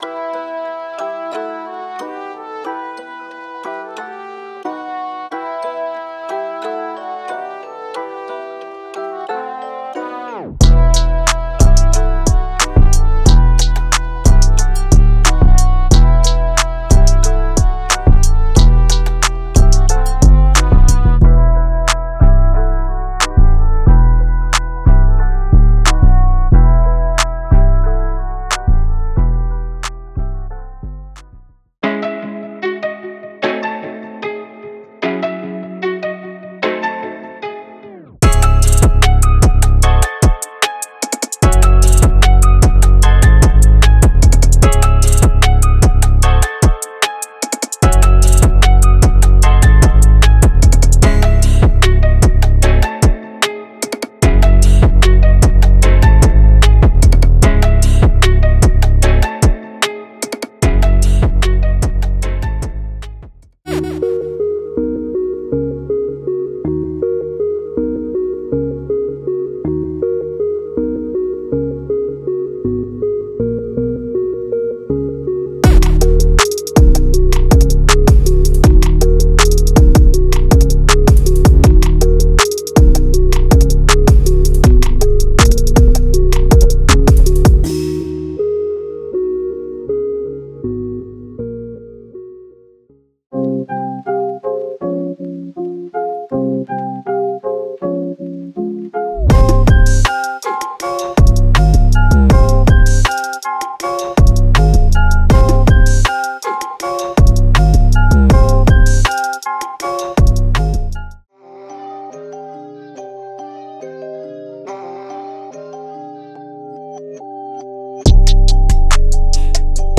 他们构建了独特的旋律循环、完整的鼓循环、强劲的808采样以及介于两者之间的各种素材，助您更快地制作出更优质的节拍。
Hip Hop采样包